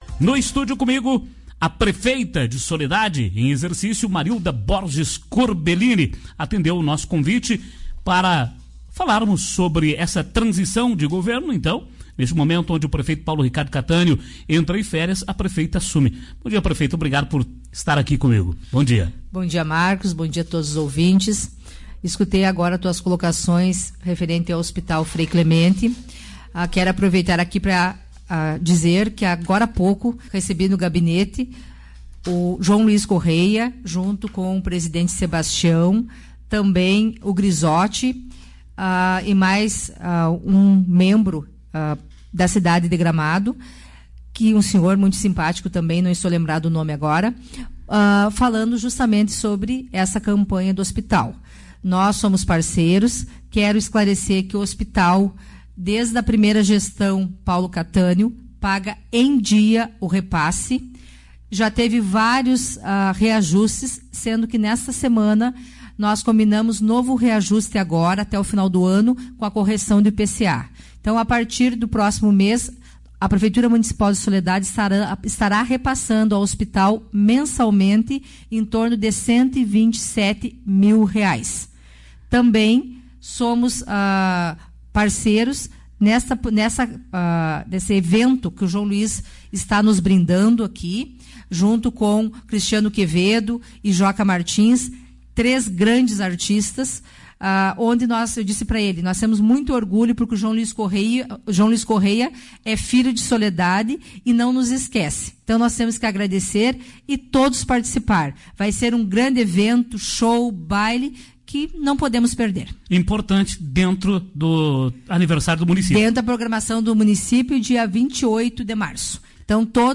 Marilda concedeu entrevista em estúdio da emissora